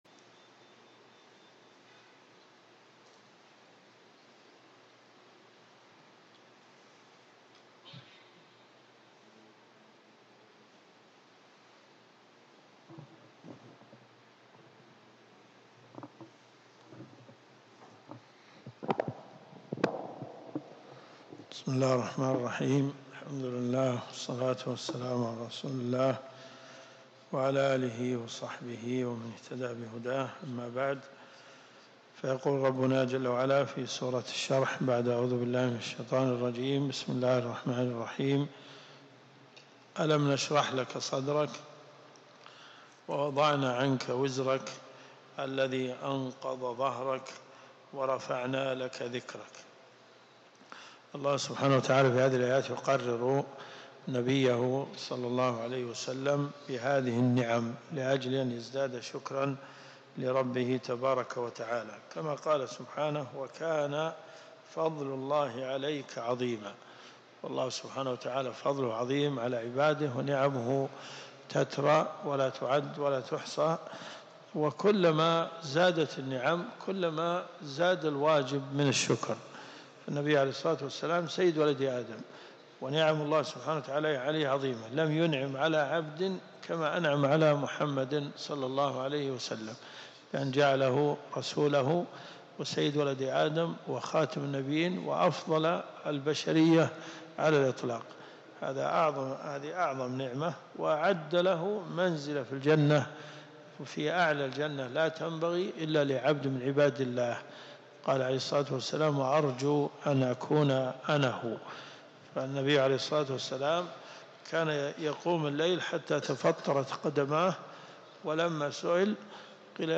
تفسير القران الكريم